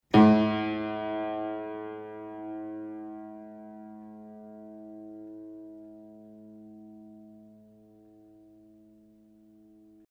I campioni sonori che seguono sono stati registrati subito dopo il montaggio dei martelli (originali e replicati), sulle rispettive meccaniche (ottobre 2002): il suono dei martelli originali (nei quali le pelli sono solcate e un po' consunte alla sommità), è come facilmente prevedibile leggermente più pungente, di quello dei martelli replicati, al momento della registrazione intatti e del tutto privi di solchi.
Ascolta LA 1 (martello replicato), quarto livello di sollecitazione meccanica